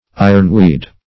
Ironweed \I"ron*weed`\, n. (Bot.)